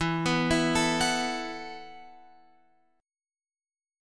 E Suspended 2nd Suspended 4th